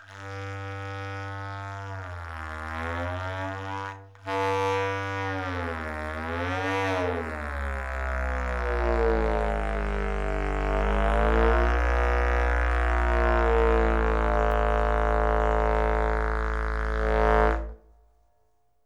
Clarinette à coulisse
Dans le cas de la clarinette à coulisse, le son est toujours une onde pleine.
La longueur de l’instrument, une fois déployée, est de 2m70 ce qui lui donne une sonorité de clarinette contre basse
SON-CLARINETTE-A-COULISSE.mp3